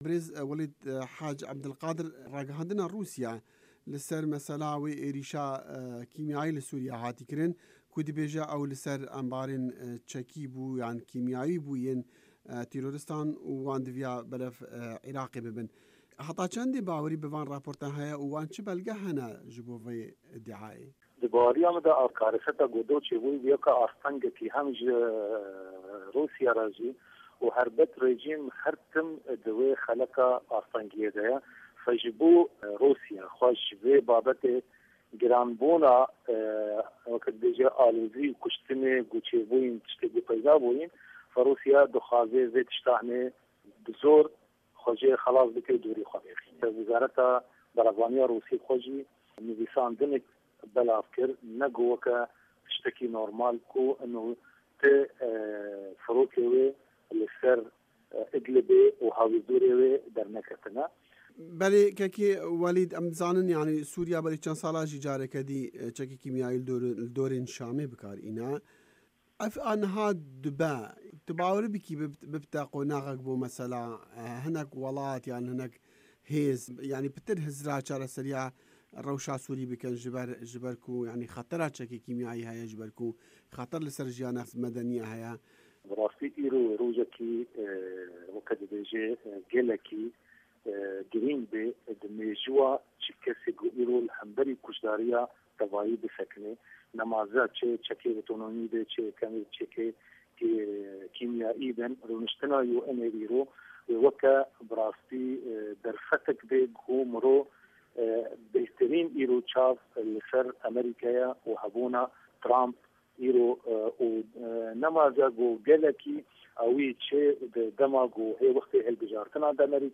Di hevpeyvînekê de digel VOA